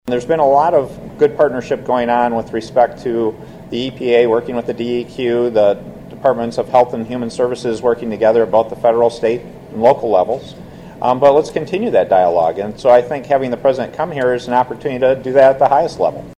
The governor emphasizing there is already a good relationship between the state and the feds.